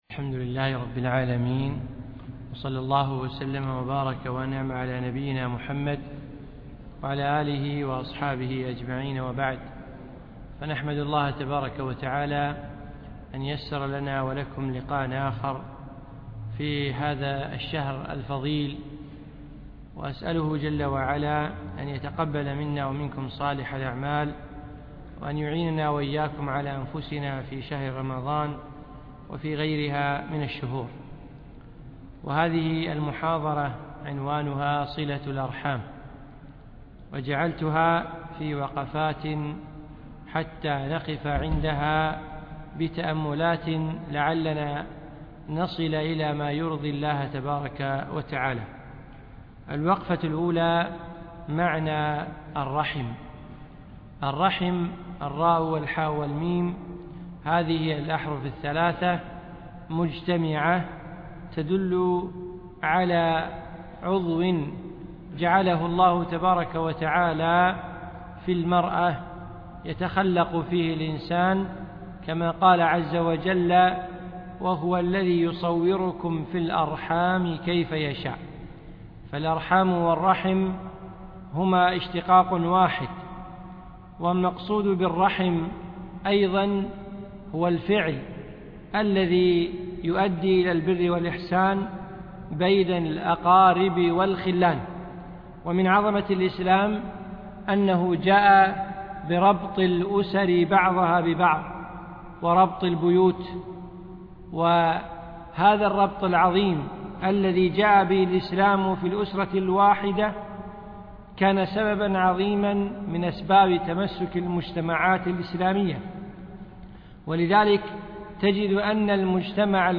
أقيمت المحاضرة في دولة الإمارات